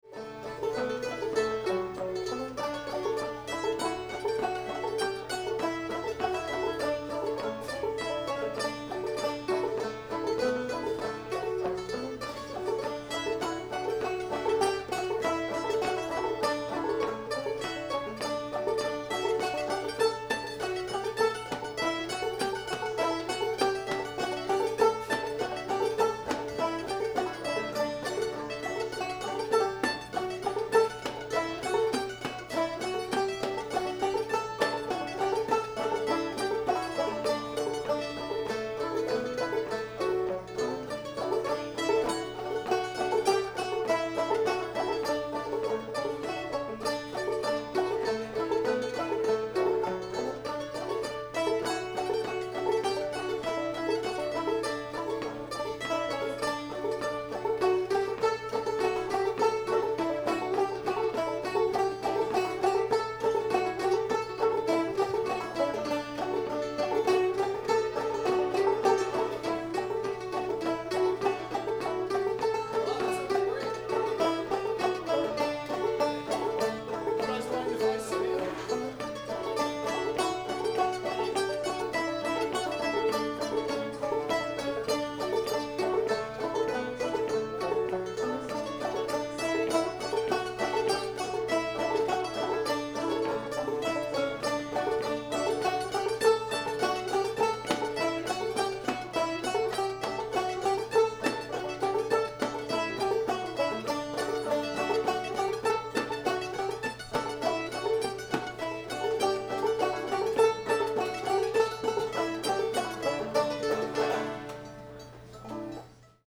yellow rose of texas [D]